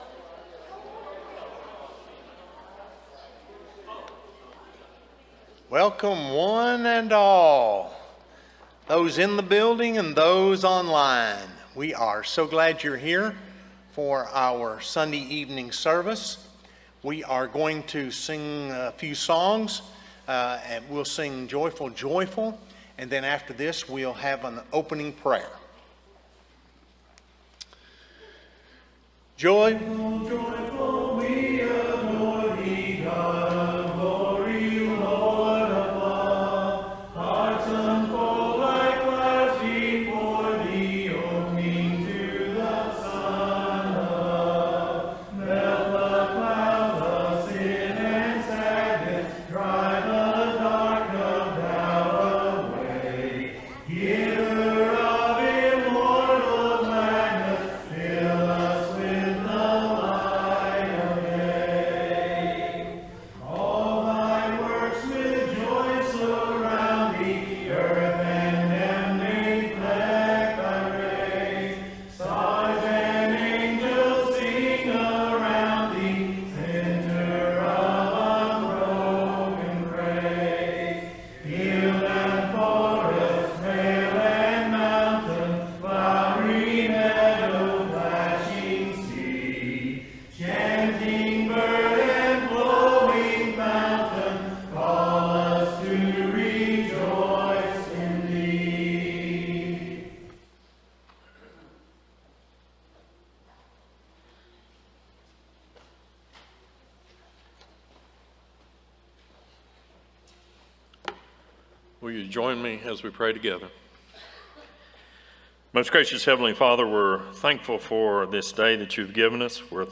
Galatians 1:13, English Standard Version Series: Sunday PM Service